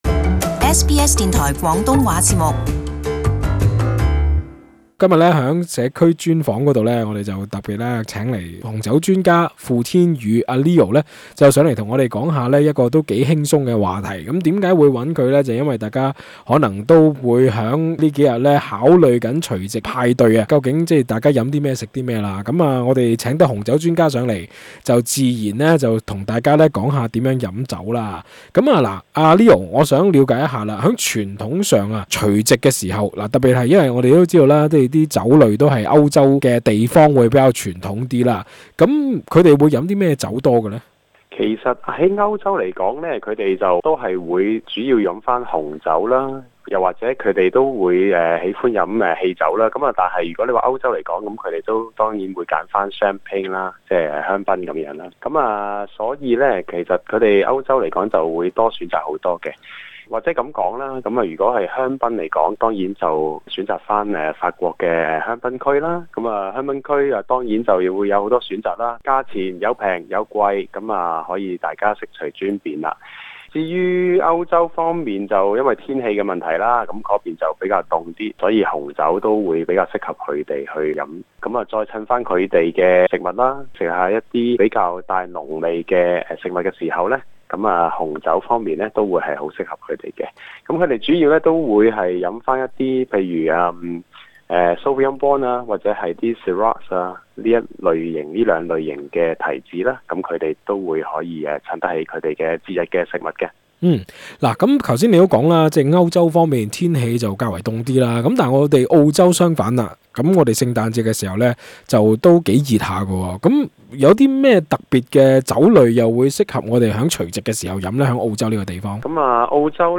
在今日的社區專訪